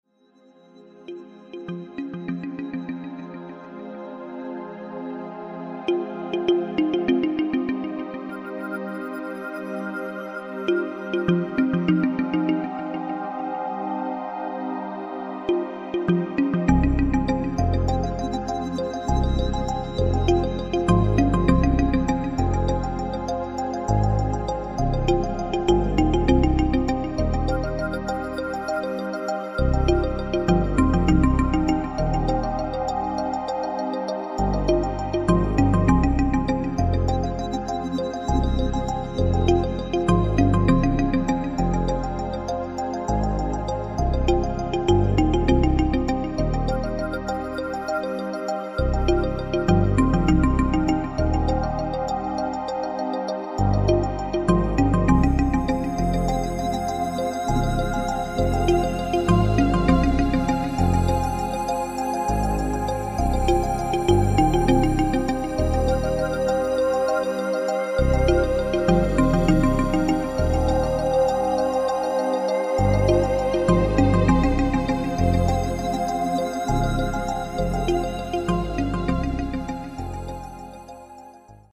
Keyboarder
atmosphärisch-verträumte Musik